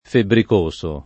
febbricoso [ febbrik 1S o ] agg.